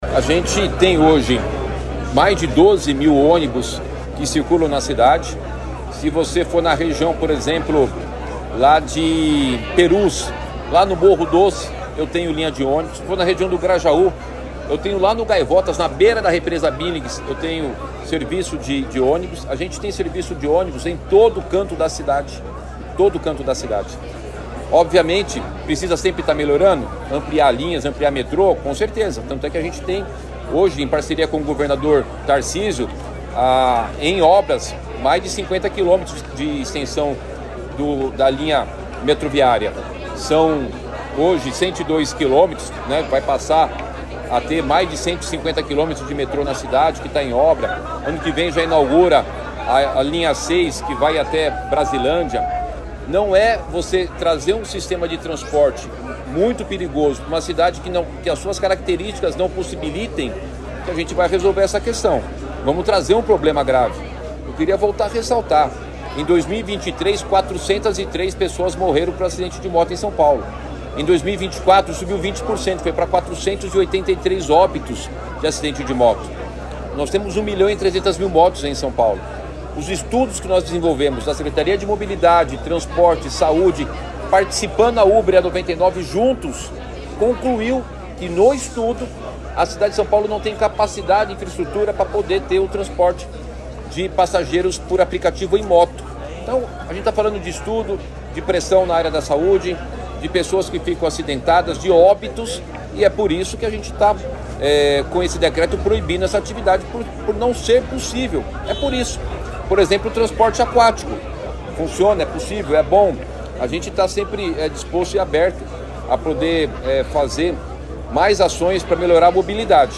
Durante coletiva, Nunes ressaltou que proibição está respaldada pela legislação federal, e ações na Justiça movidas contra a norma partiram de uma entidade “falsa”, sem legitimidade para representar a categoria